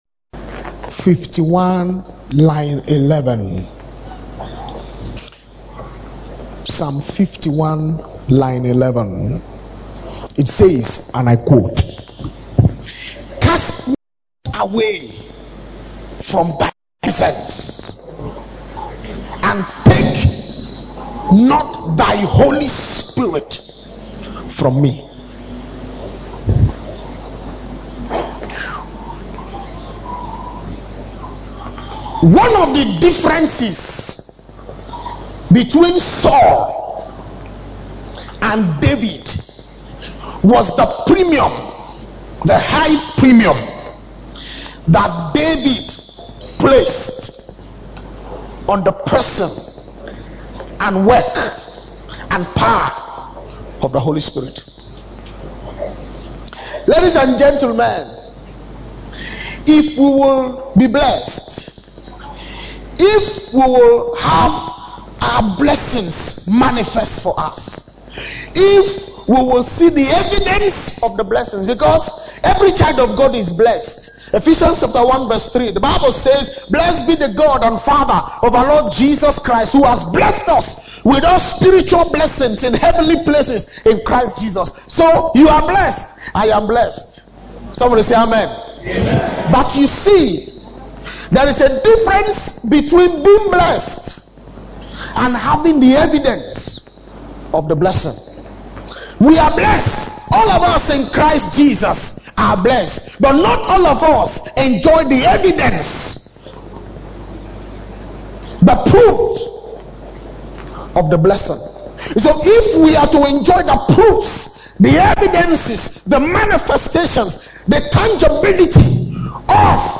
Bible Verses: Psalm 51:11, Ephesians 1:3 Posted in Breakthrough Service